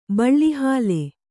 ♪ baḷḷi hāle